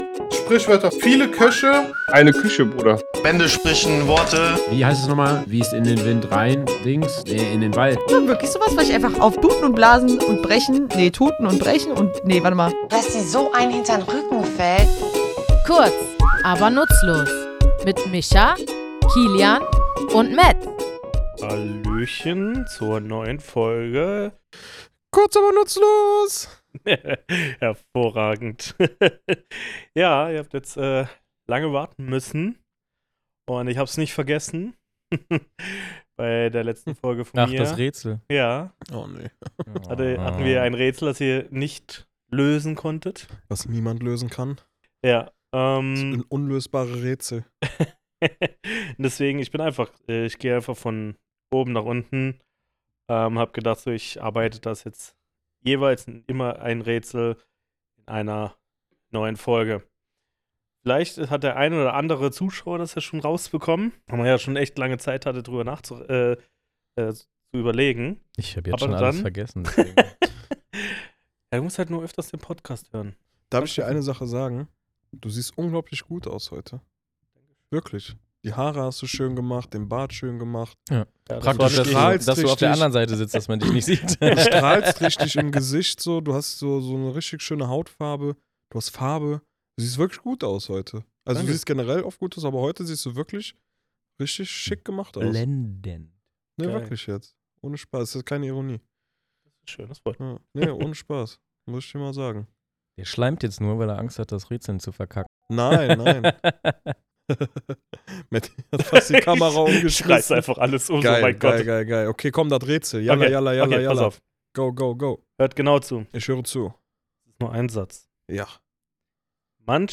Beschreibung vor 1 Jahr Episodenbeschreibung für Folge 27: “Auf die Barrikaden gehen” In der siebenundzwanzigsten Folge beschäftigen wir uns mit der Redewendung „auf die Barrikaden gehen“. Was bedeutet es, wenn Menschen lautstark protestieren, und woher stammt dieser Ausdruck eigentlich? Wir, drei tätowierende Sprachfans, nehmen euch in unserem Tattoostudio mit auf eine Reise durch die Geschichte dieser kämpferischen Redensart.